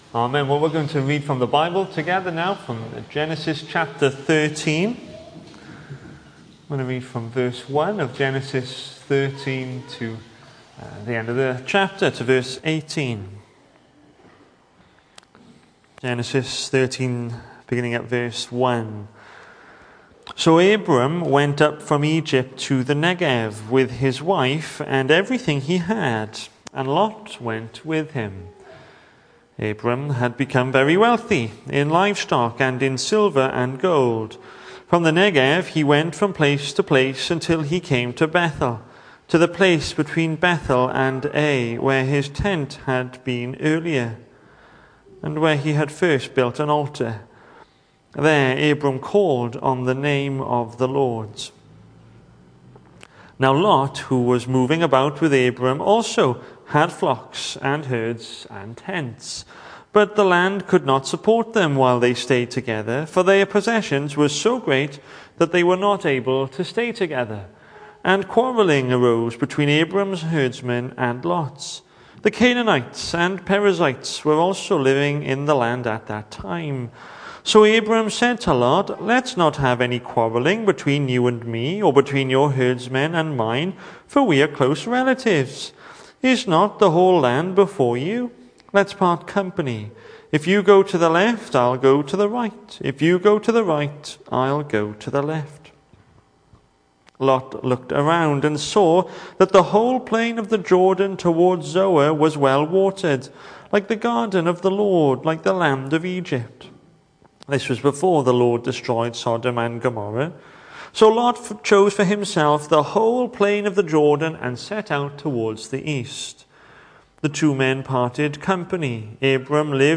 The 14th of September saw us hold our evening service from the building, with a livestream available via Facebook.